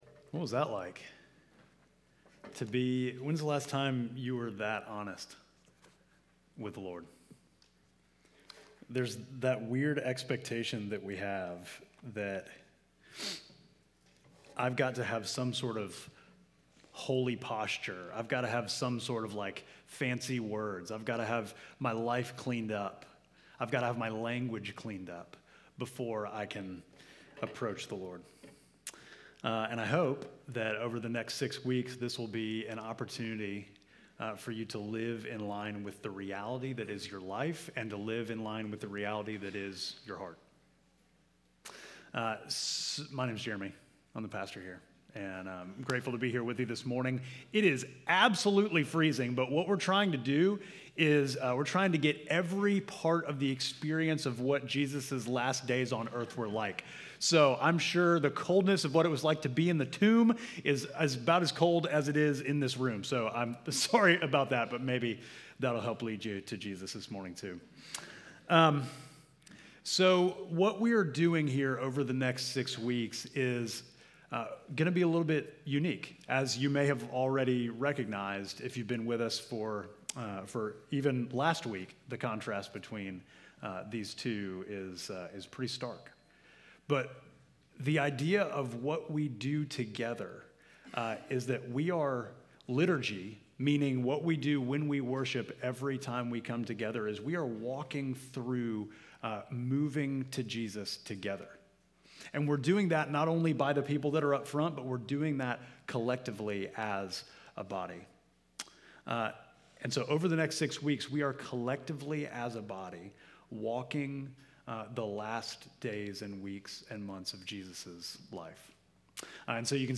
Midtown Fellowship Crieve Hall Sermons Peace on Earth Mar 09 2025 | 00:41:53 1x 00:00 / 00:41:53 Subscribe Share Apple Podcasts Spotify Overcast RSS Feed Share Link Embed